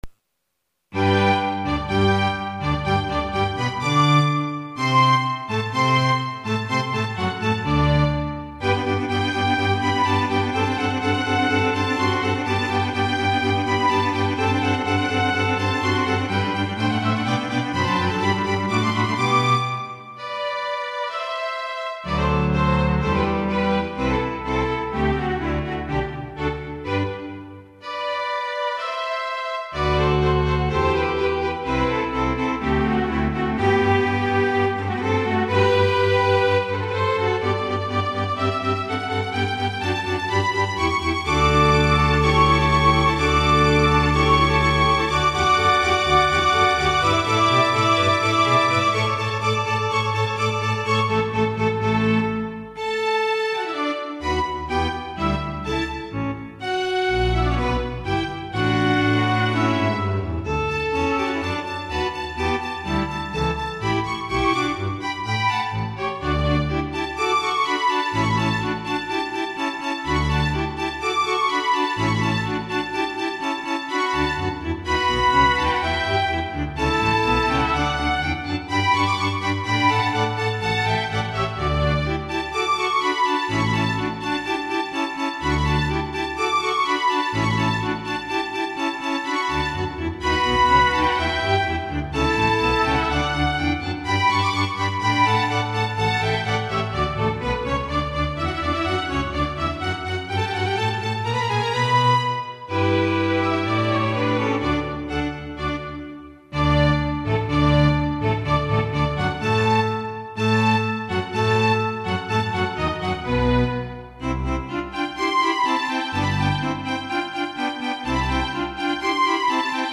クラシック曲
Wave：MIDI音源 Roland SK-88Pro 44100Hz 16bit STEREO